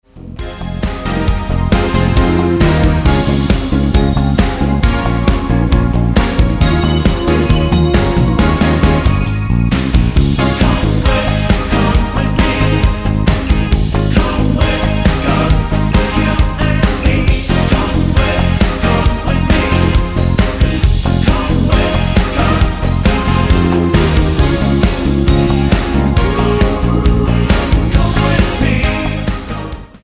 Tonart:Ab-A mit Chor